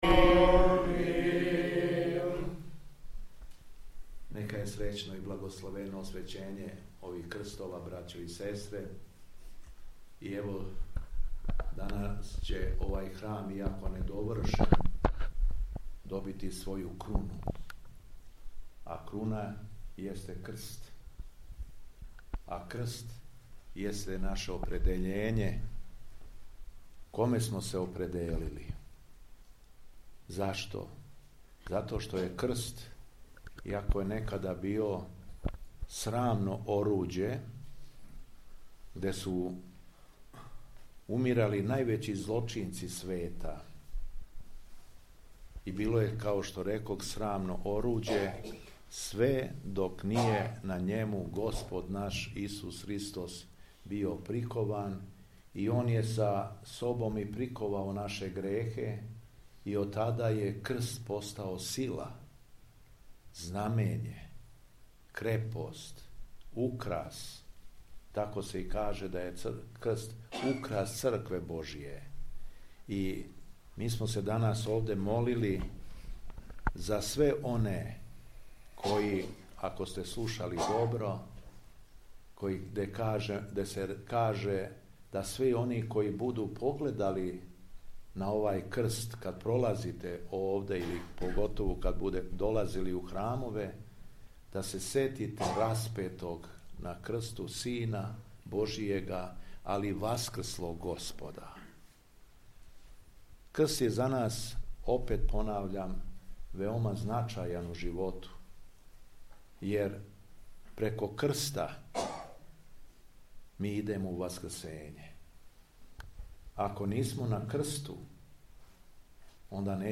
Дана 13. октобра 2025. године, на празник Светог свештеномученика Григорија и Светог Михаила Кијевског, у поподневним часовима Његово Високопреосвештенство Архиепископ крагујевачки и Митрополит Шумадијски Господин Г. Јован освештао је крстове за новоподигнути храм Светог Василија Острошког у Урсулама, у присуству свештенства и верног народа.
Беседа Његовог Високопреосвештенства Митрополита шумадијског г. Јована